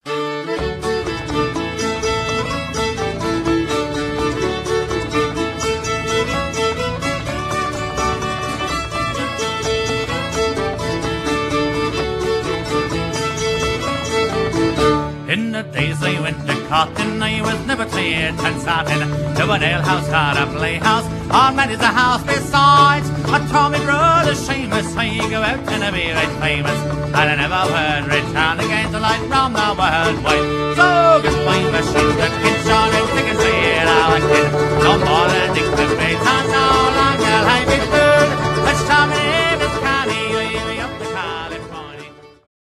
song-reel